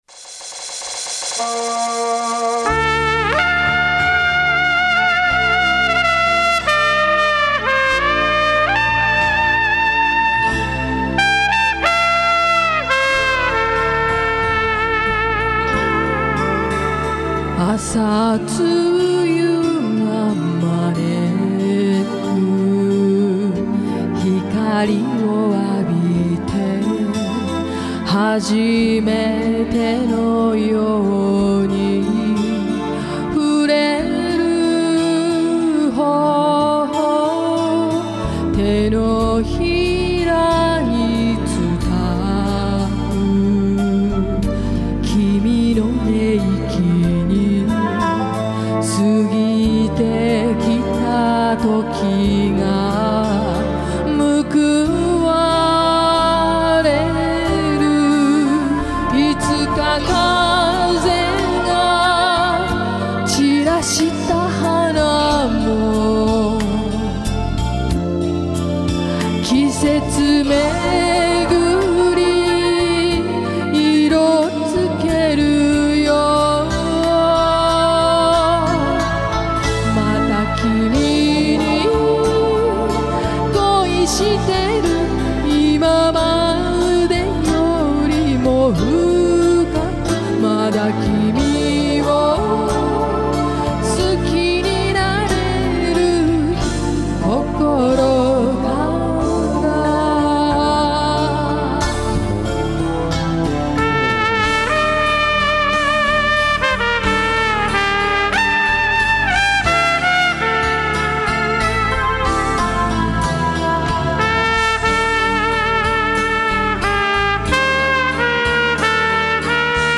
カミさんとの共演です。
僕の音（とカミさんの声）
まだ、歌もラッパもちょっと不安定ですが、年末までにはもう少し落ち着くでしょう・・たぶん。